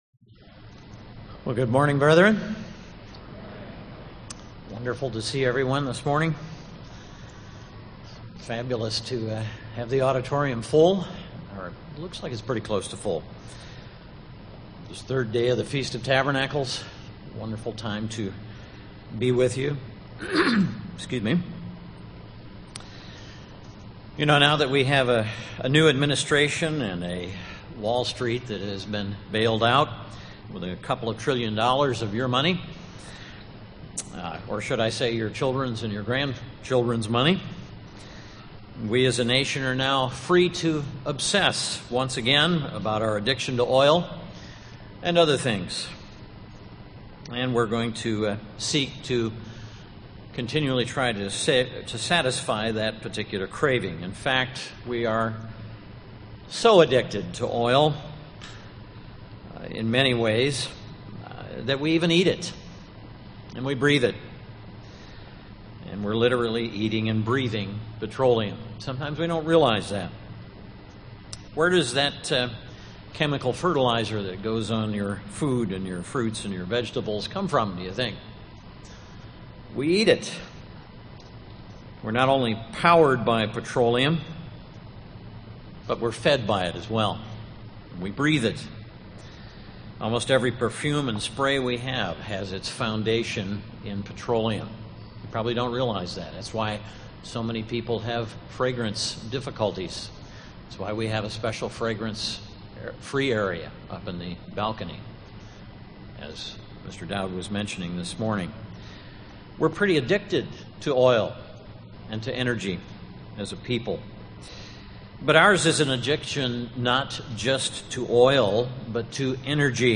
This sermon was given at the Wisconsin Dells, Wisconsin 2009 Feast site.